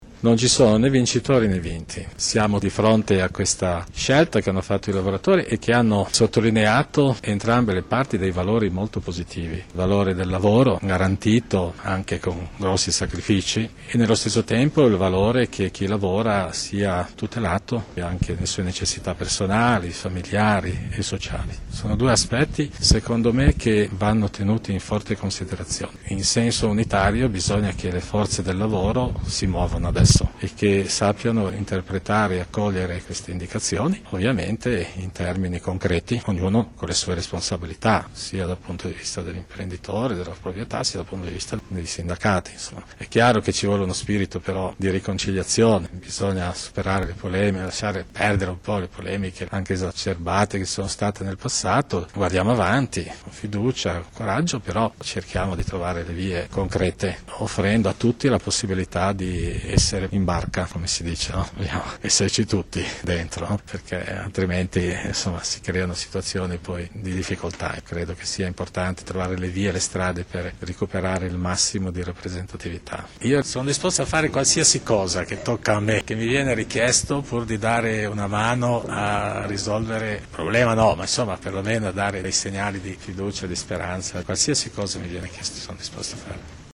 Sabato 15 gennaio, al Santuario della Consolata, mons.Cesare Nosiglia ha proposto una preghiera per il mondo del lavoro con lo scopo di chiedere a Dio, per intercessione della Vergine Maria, che cresca la volontà di riprendere il cammino in spirito di riconciliazione e di dialogo e con il coinvolgimento di tutte le parti sociali.